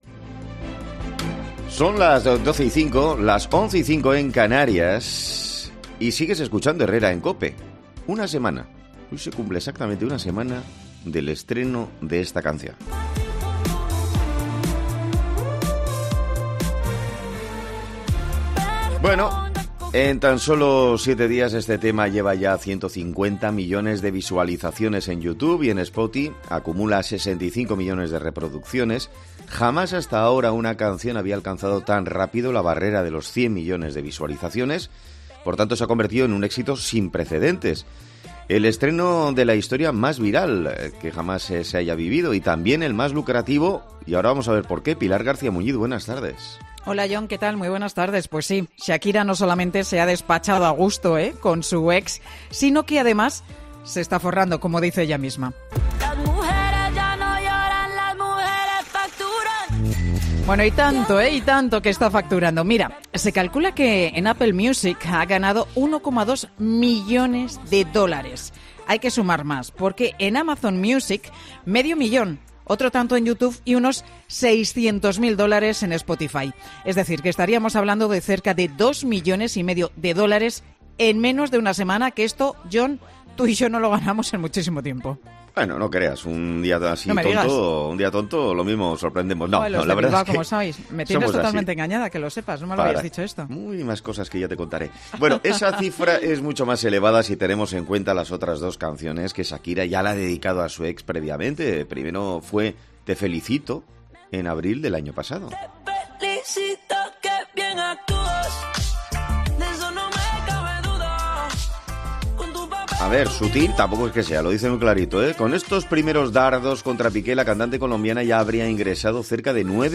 'Herrera en COPE' habla con un cantante español para saber cómo funciona la remuneración de los artistas en las plataformas digitales por sus canciones